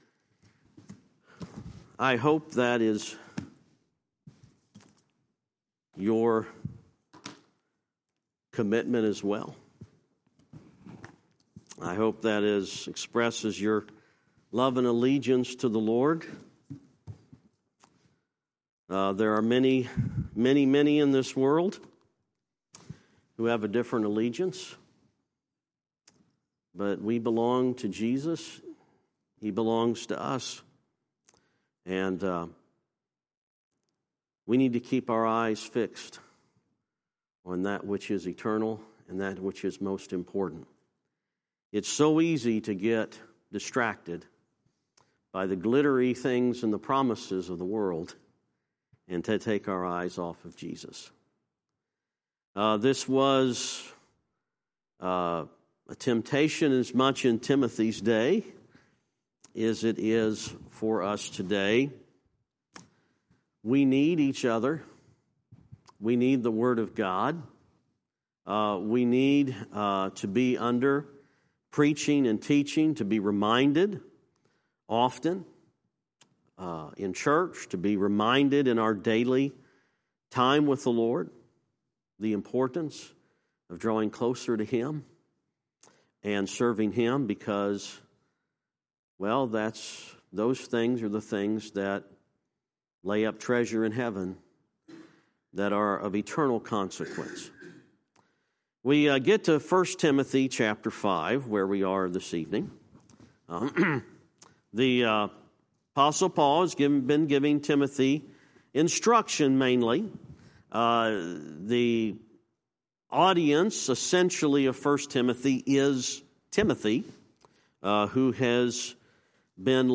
I Timothy Lesson 16